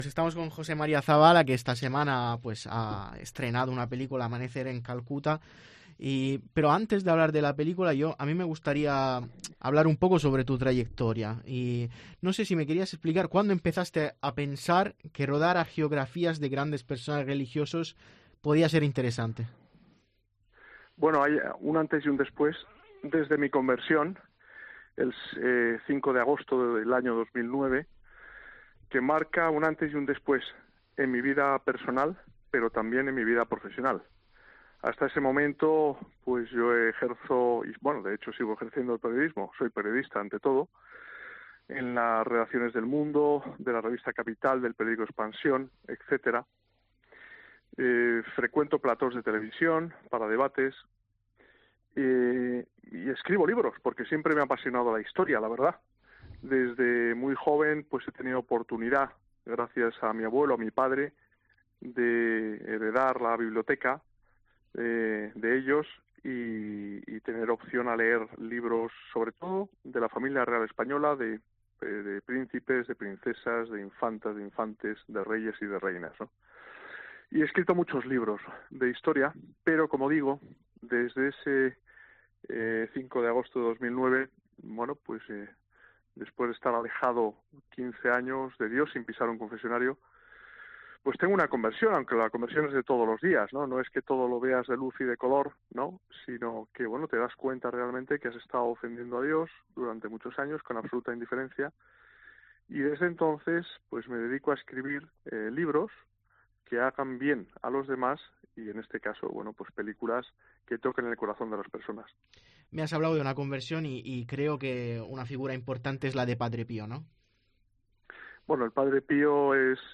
En una entrevista concedida a 'Aleluya'